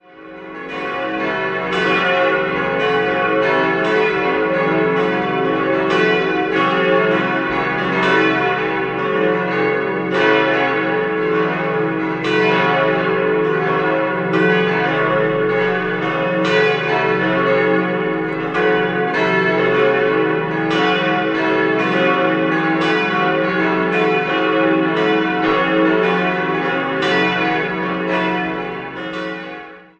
6-stimmiges Geläut: cis'-e'-fis'-gis'-h'-c'' Die vier größeren Glocken wurden 1955 von Karl Czudnochowsky in Erding gegossen, Nr. 5 von Rincker im Jahr 1900. Die kleinste ist eigentlich nicht Teil des Hauptgeläuts und wurde 1653 von Franziskus Voillard gegossen.